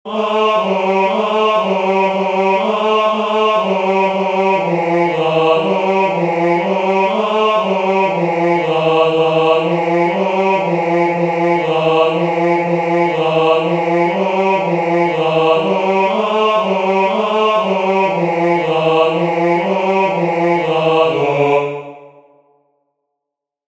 The first chant of Rauner 003203 folio 017r "Gloria patri," from the second nocturn of Matins, Common of Apostles
"Gloria patri," from the second nocturn of Matins, Common of Apostles